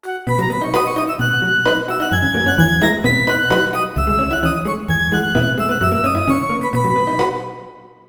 Tonalidad de Si mayor. Ejemplo.
desenfadado
festivo
jovial
melodía
sintetizador